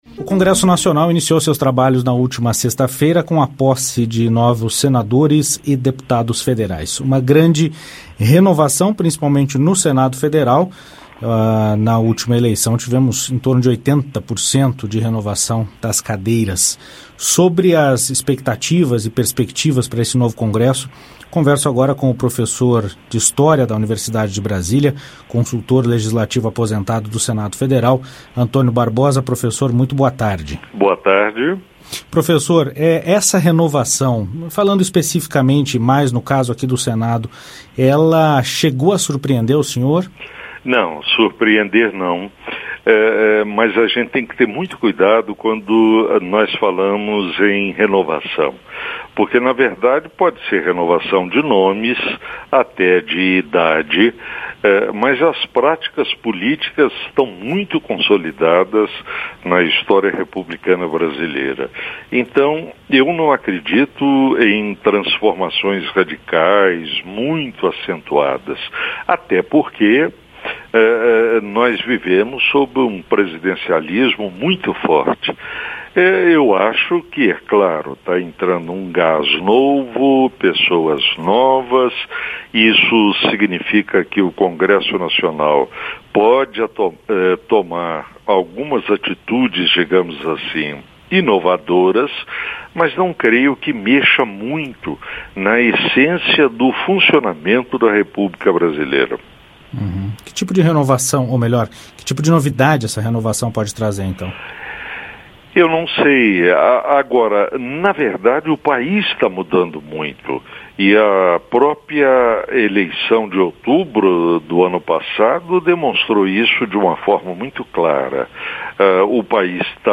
Programa jornalístico que trata da agenda do Congresso Nacional, com entrevistas, comentários e reportagens sobre os principais assuntos em debate e em votação.